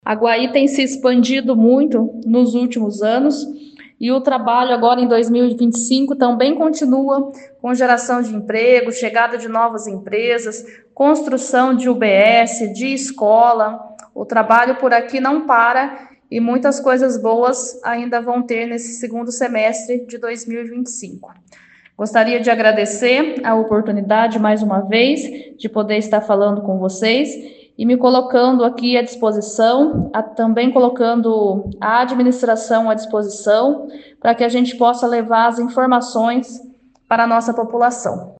Em entrevista à 92FM